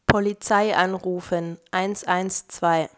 Nexdata/German_Speech_Data_by_Mobile_Phone_Guiding at main